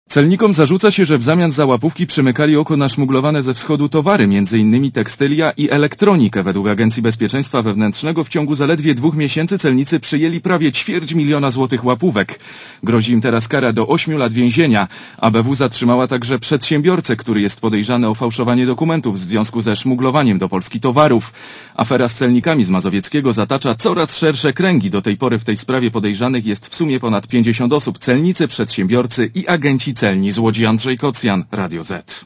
Relacja reportera Radia Zet (130Kb)